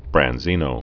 (brăn-zēnō)